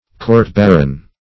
Search Result for " court-baron" : The Collaborative International Dictionary of English v.0.48: Court-baron \Court"-bar`on\ (-b?r`?n), n. (Law) An inferior court of civil jurisdiction, attached to a manor, and held by the steward; a baron's court; -- now fallen into disuse.